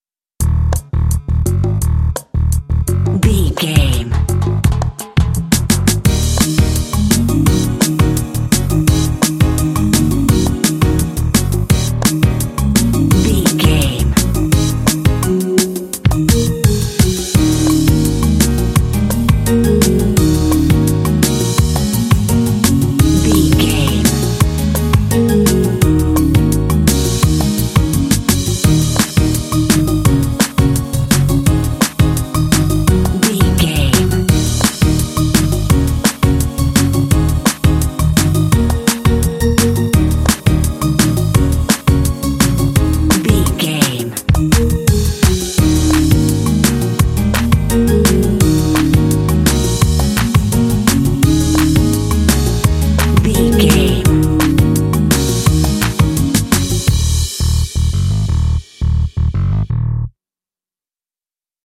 Uplifting
Dorian
E♭
Fast
funky
groovy
smooth
energetic
synthesiser
piano
drums
bass guitar
Funk